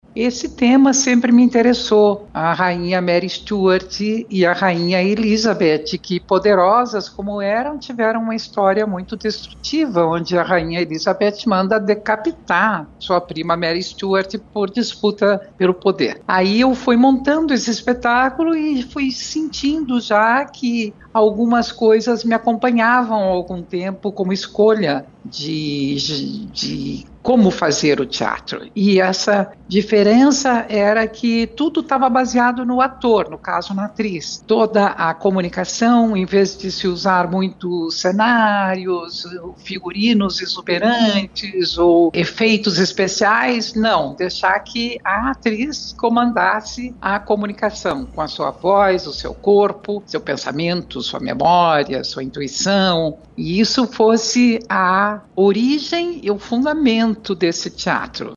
Denise explica qual foi a inspiração para criar o teatro, e como foi a montagem da peça.